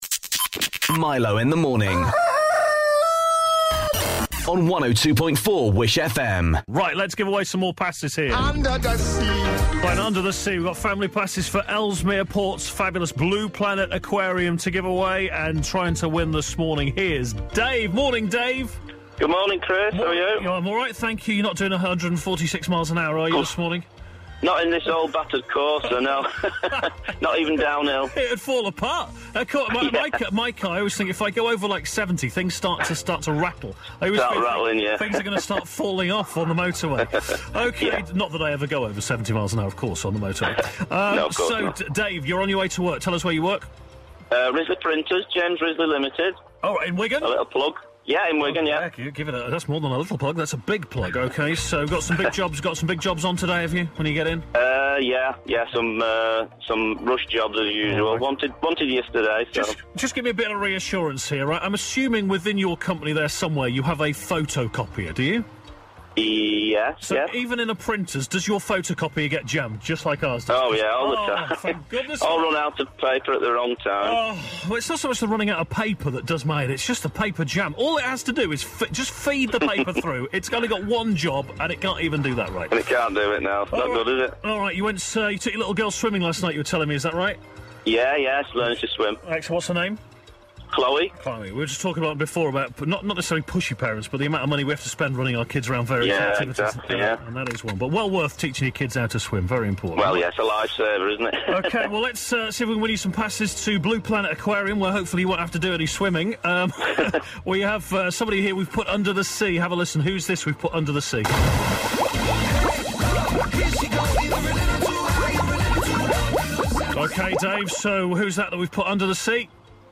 If you ever call up and appear on the show we always upload the best bits here so you can listen again later